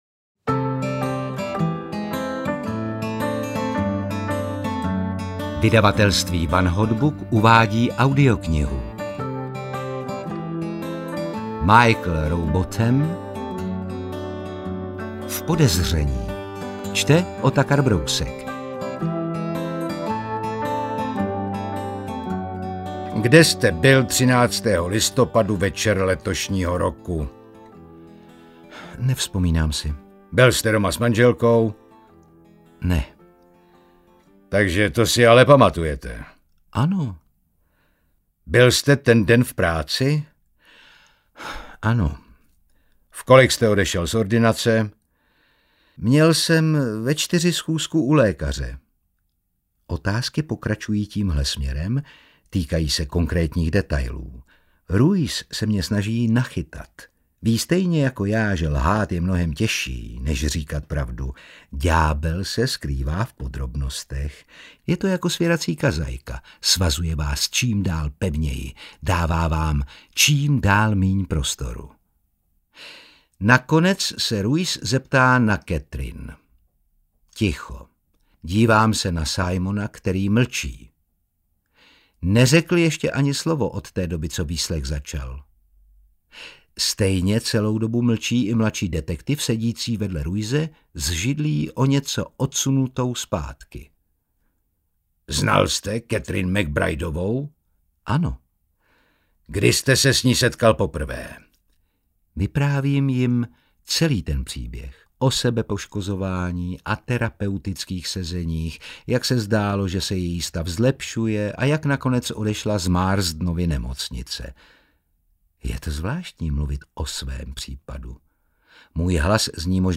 V podezření audiokniha
Ukázka z knihy
• InterpretOtakar Brousek ml.